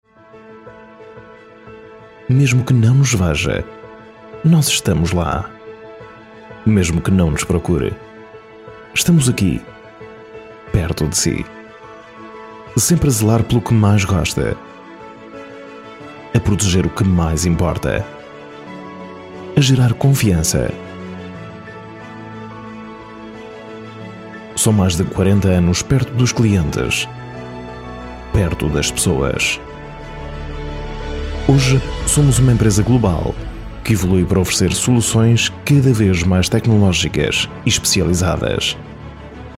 Commerciale, Naturelle, Polyvalente, Fiable, Amicale
Corporate
Calm, deep recording, aggressive sales voice, lively, friendly, conversational.